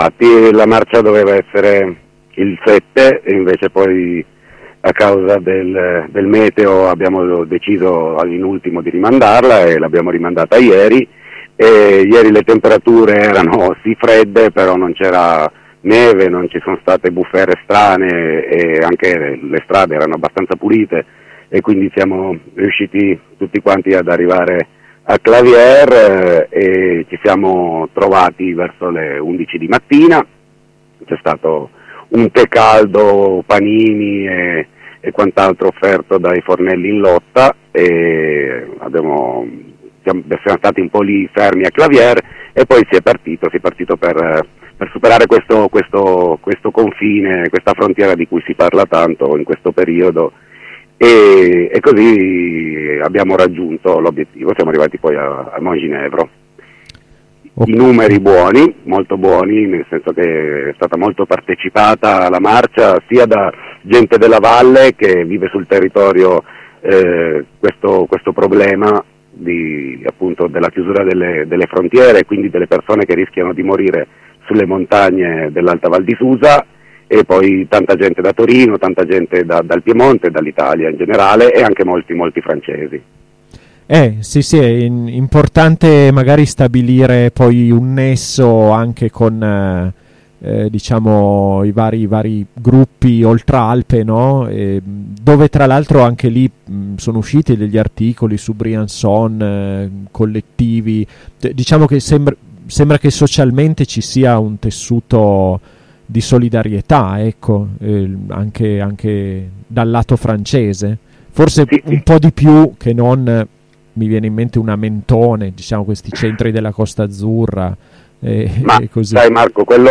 Buona partecipazione alla marcia promossa domenica 14 gennaio dalla rete "Briser les frontieres" sul confine italo francese in Alta Val Susa. Prosegue anche l'attività di monitoraggio della rete, per tutelare chi rischia la vita cercando di bruciare la frontiera, alla ricerca di un futuro migliore. Ascolta la diretta